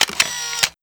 shutter.wav